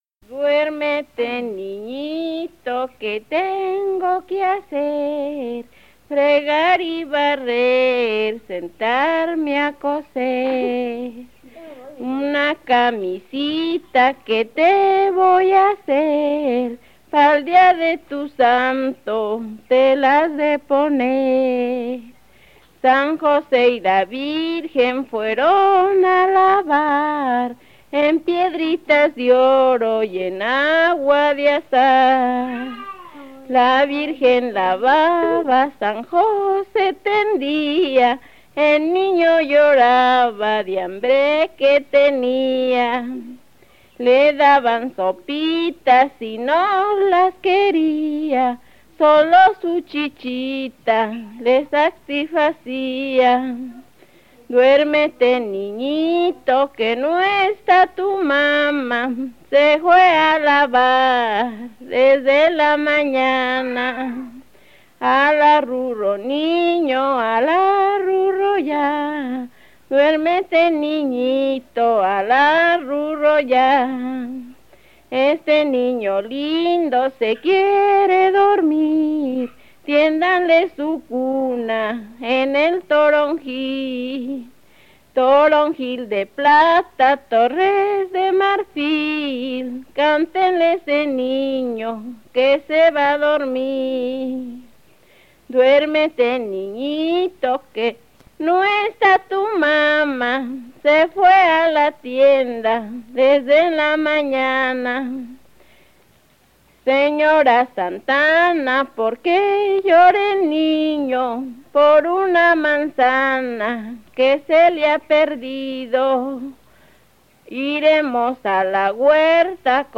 Keywords: música de navidad
Description: Varios intérpretes. Grabaciones de campo
arrullo